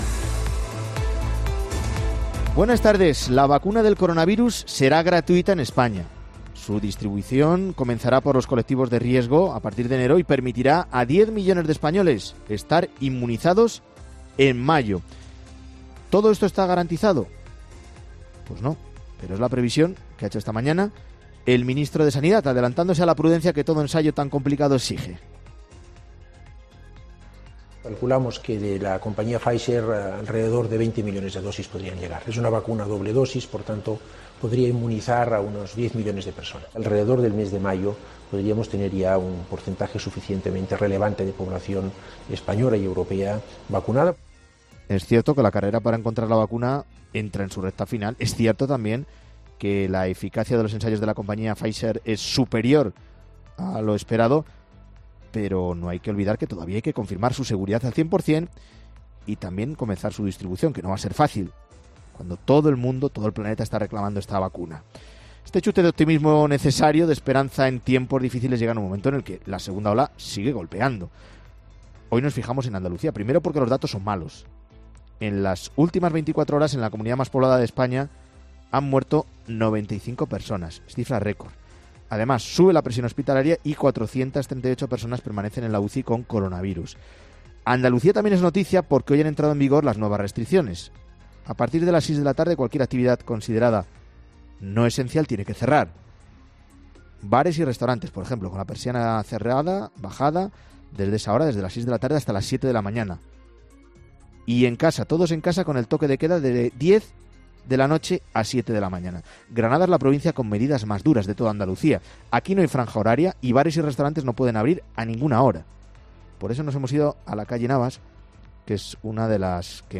Por eso nos hemos ido a la calle Navas, que es una de las que más movimiento tiene en Granada.